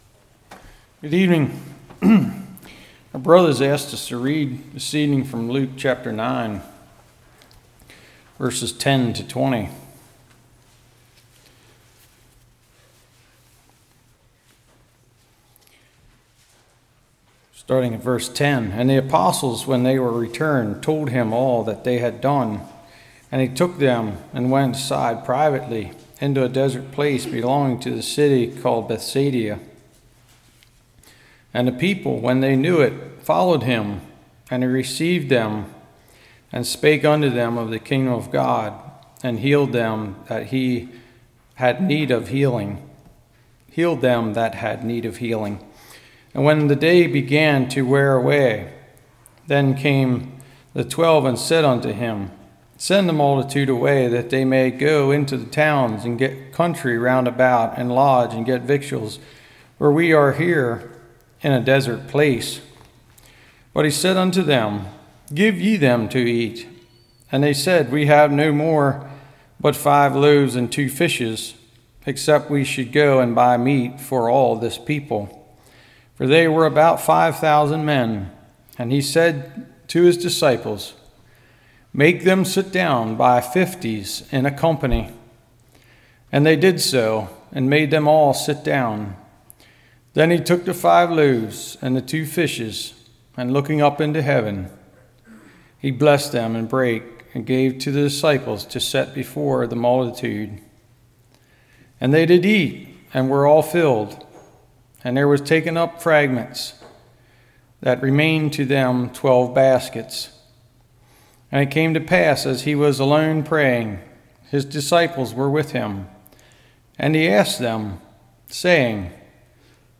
Luke Series Passage: Luke 9:10-20 Service Type: Evening Go To God In Prayer.